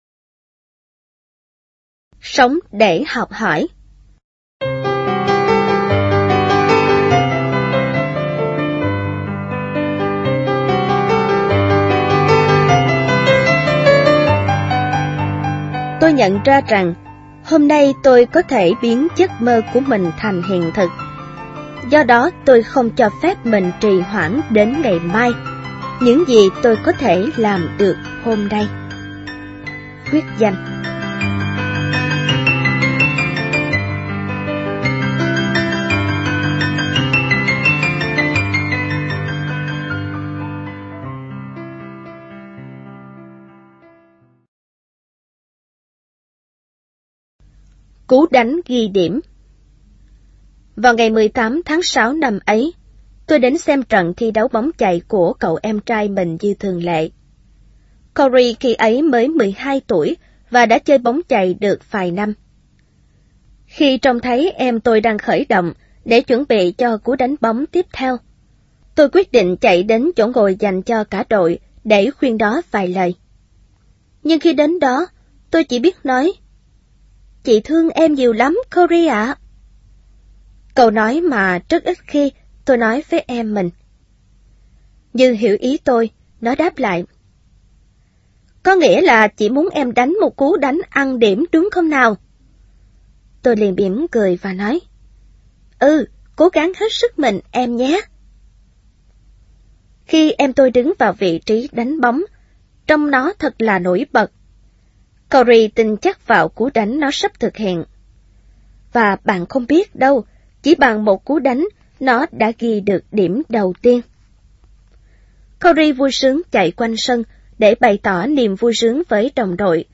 Sách nói Chicken Soup 4 - Chia Sẻ Tâm Hồn Và Quà Tặng Cuộc Sống - Jack Canfield - Sách Nói Online Hay